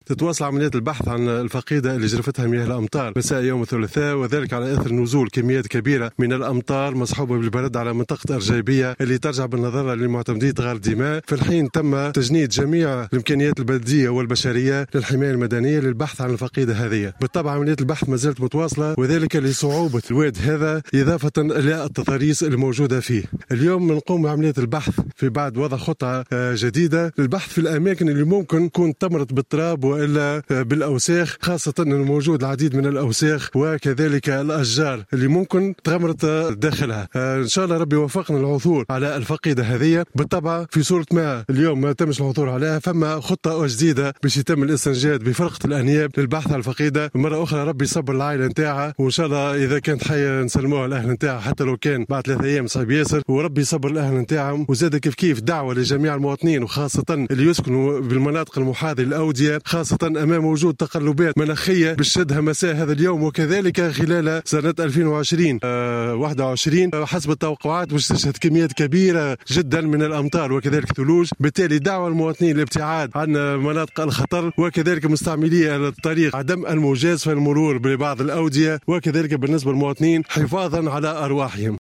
المدير الجهوي للحماية المدنية بجندوبة